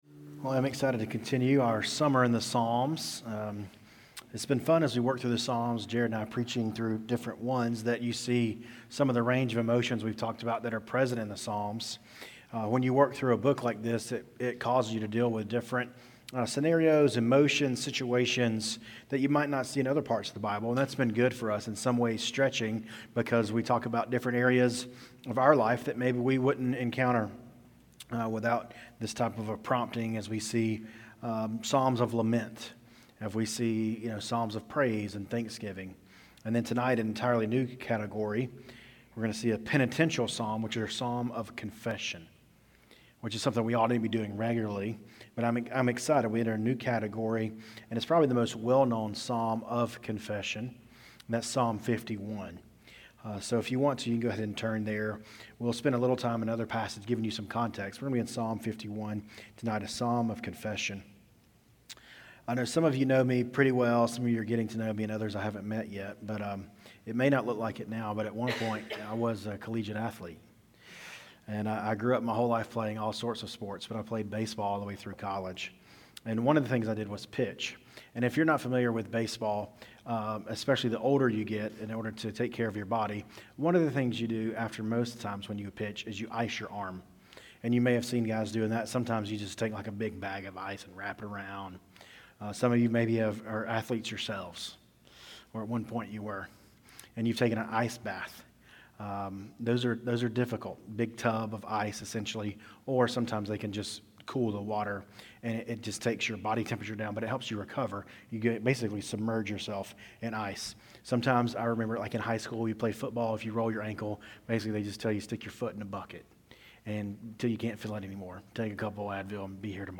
City View Church - Sermons